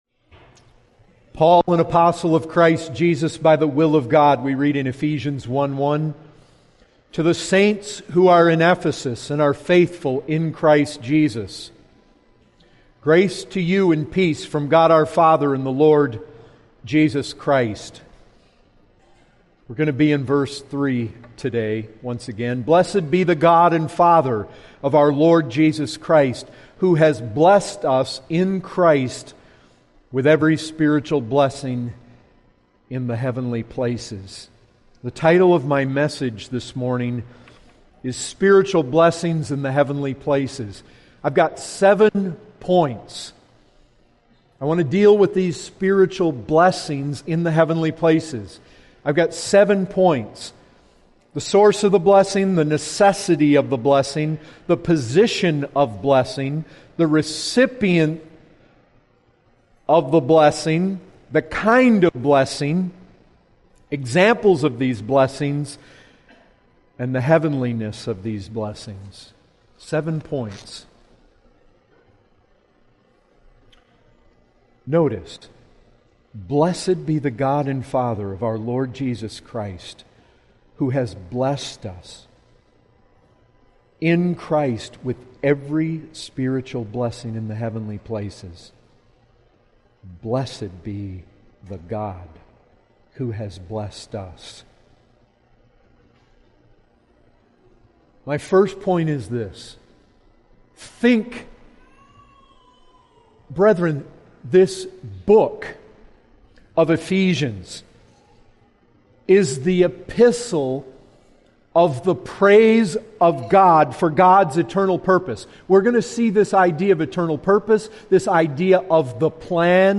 Full Sermons